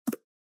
ButtonSFX.ogg